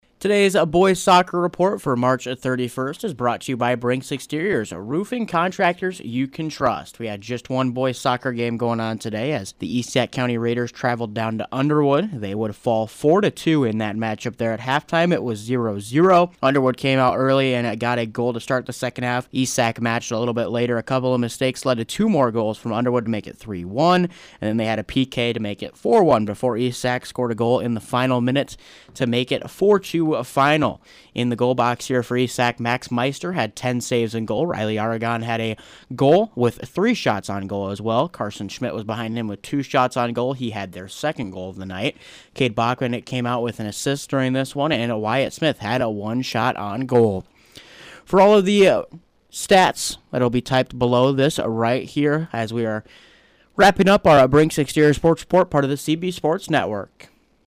boys-soccer-report-for-3-31.mp3